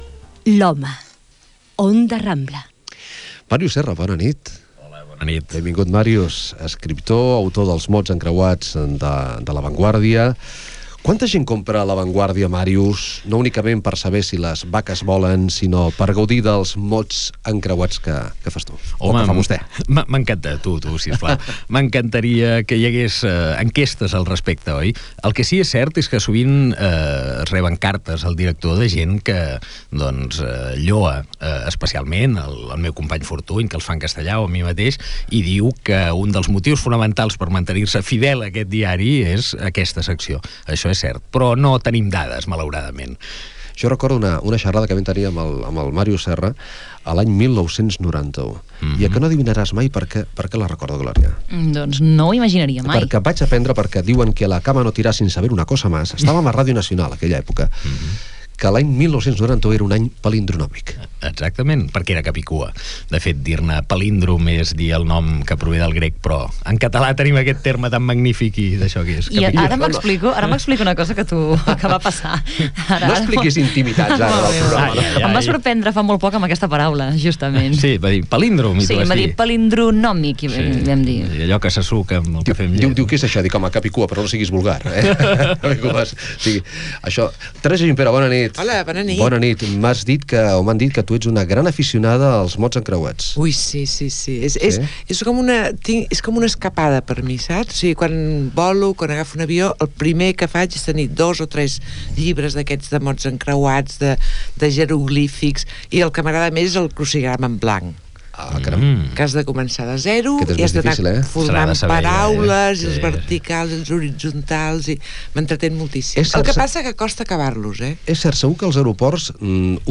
Indicatiu del programa. Conversa amb l'escriptor Màrius Serra sobre els seus mots encreuats al diari La Vanguardia, els jocs de paraules i el llibre "La vida normal" amb la participació de l'actriu Teresa Gimpera